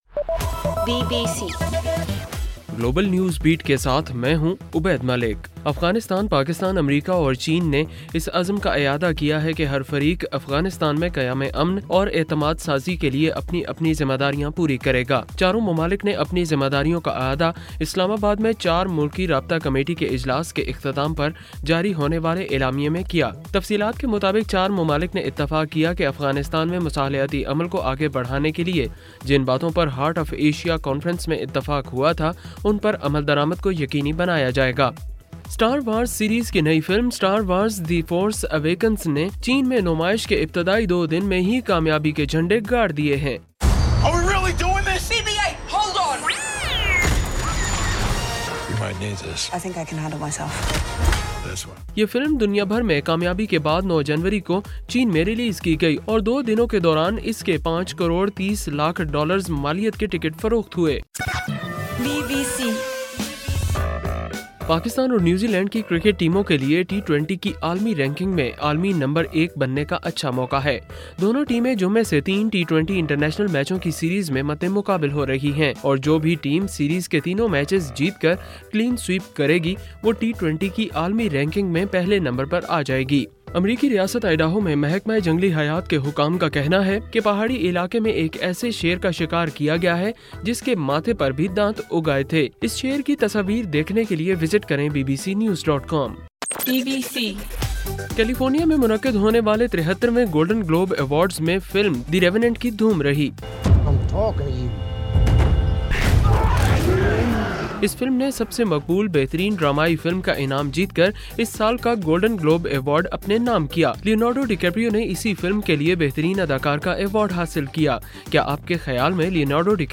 جنوری 11: رات 12 بجے کا گلوبل نیوز بیٹ بُلیٹن